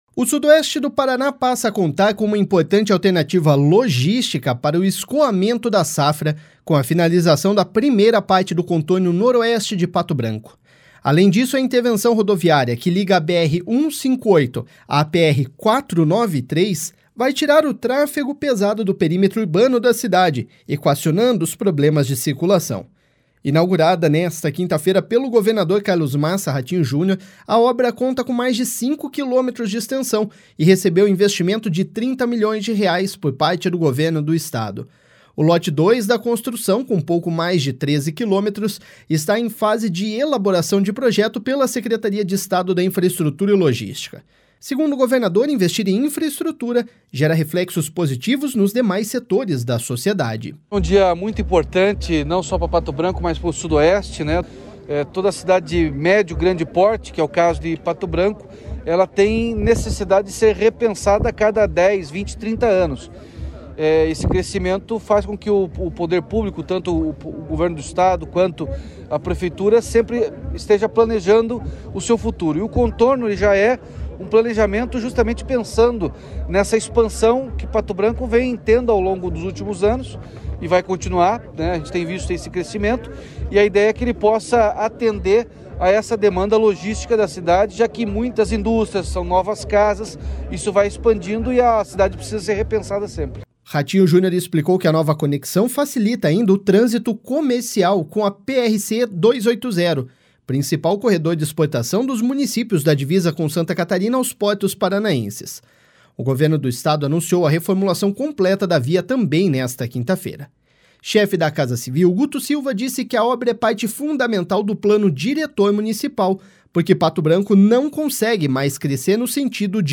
Segundo o governador, investir em infraestrutura gera reflexos positivos nos demais setores da sociedade.// SONORA RATINHO JUNIOR.//
Ele ainda lembrou que o Governo do Estado tem dado atenção a todas as regiões, buscando fortalecer o Paraná como um todo.// SONORA GUTO SILVA.//
Já o prefeito de Pato Branco, Robson Cantu, lembrou que o projeto original tramita no Governo do Estado desde 2013, e a maior parte da obra foi executada entre 2019 e 2020.// SONORA ROBSON CANTU.//